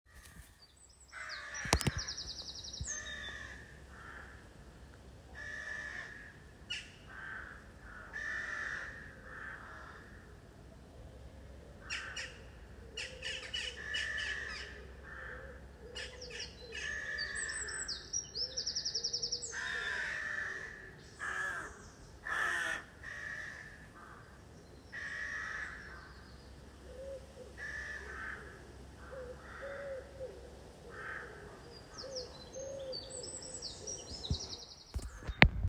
Listen to a recording of rook cries at 5:00 in the morning outside resident hall 2.